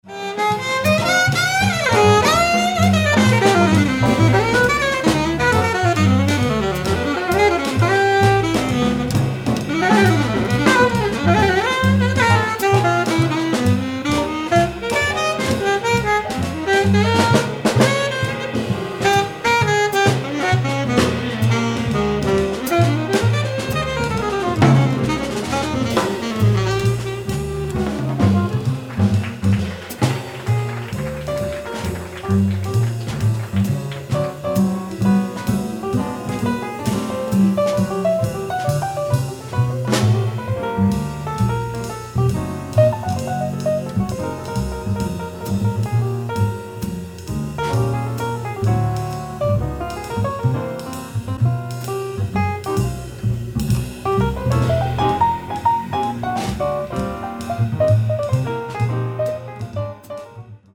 piano
acoustic bass
drums
modern mainstream jazz